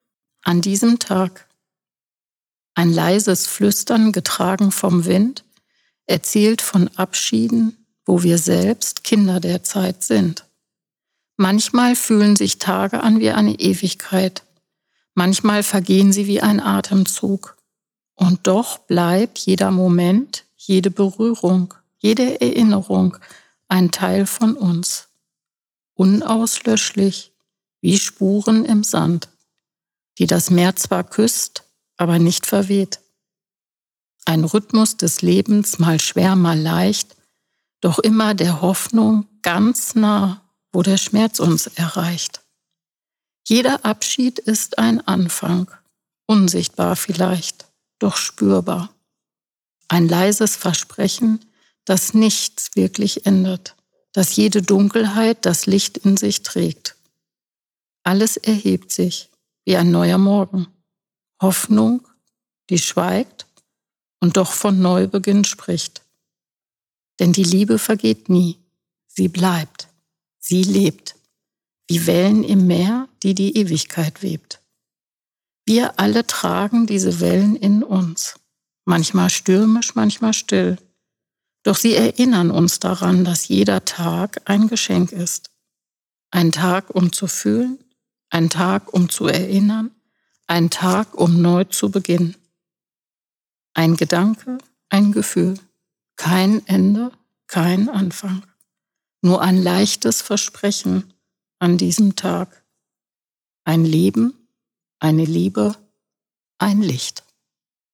Trauerrede würdevoll leicht gemacht
trauerfeier.mp3